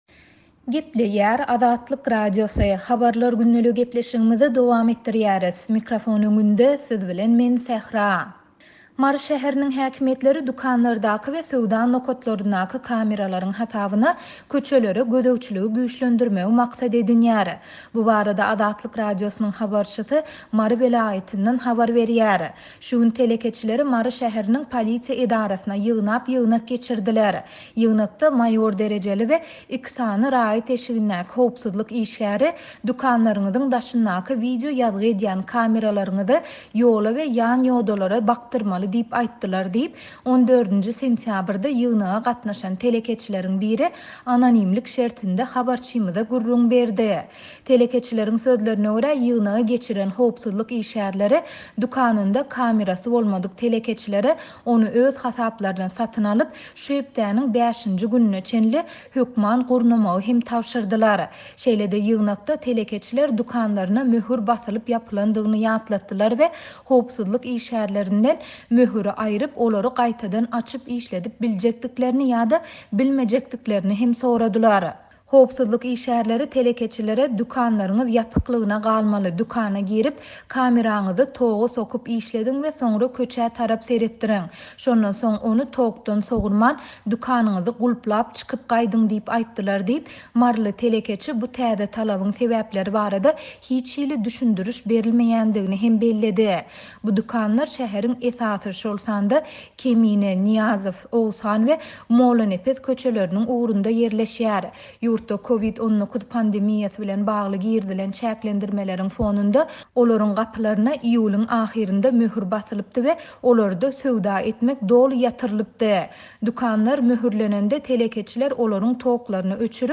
Mary şäheriniň häkimiýetleri hususy dükanlardaky we söwda nokatlaryndaky kameralaryň hasabyna köçelere gözegçiligi güýçlendirmegi maksat edinýär. Bu barada Azatlyk Radiosynyň habarçysy Mary welaýatyndan habar berýär.